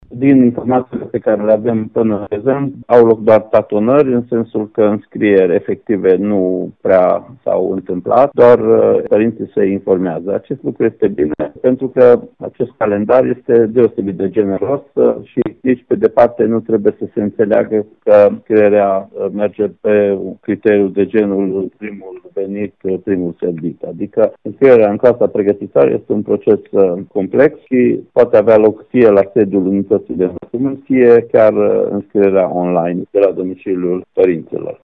Procesul de înscriere se derulează până în 13 martie, astfel că inspectorul şcolar general al judeţului Mureş, Ştefan Someşan, le transmite părinţilor să nu se grăbească, dar să nu lase înscrierea pe ultimele zile, când s-ar putea crea aglomeraţie.